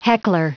Prononciation du mot heckler en anglais (fichier audio)
Prononciation du mot : heckler